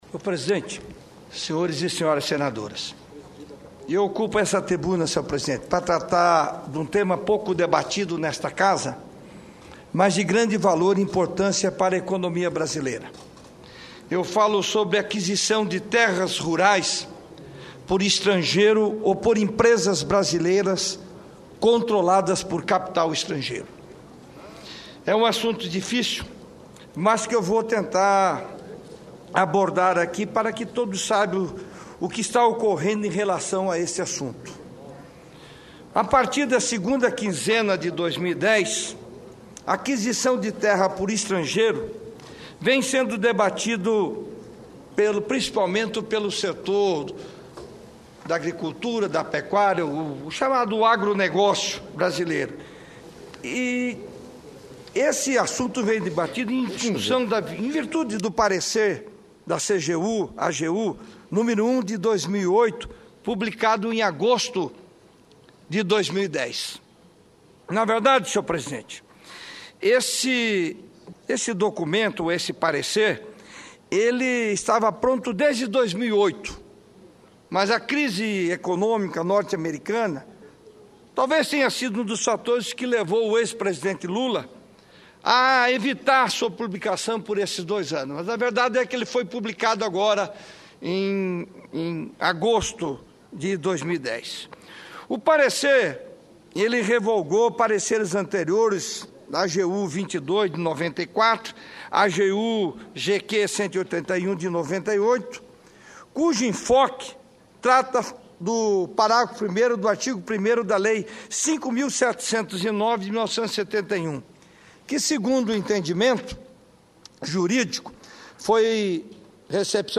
O senador Waldemir Moka (PMDB-MS) falou sobre a aquisição de propriedades rurais por estrangeiros ou por empresas brasileiras controladas por capital estrangeiro.